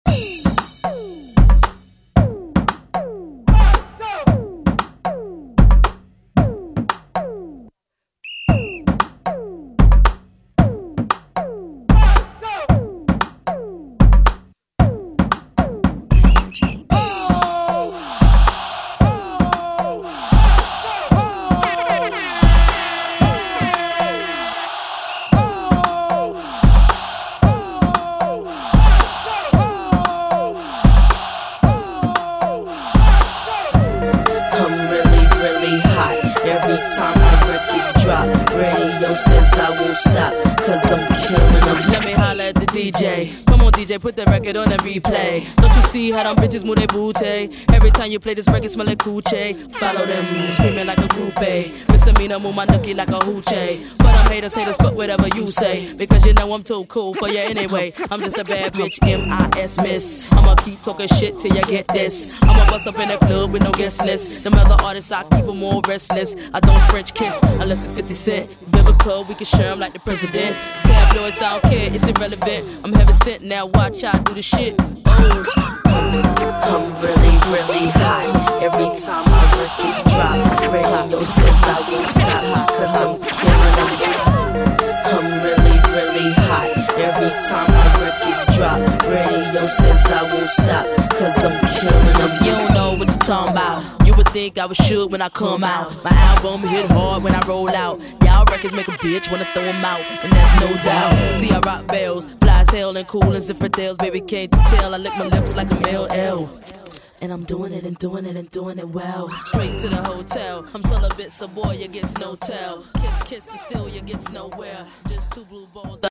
Classic Rock Music
Genre: 60's
Clean BPM: 134 Time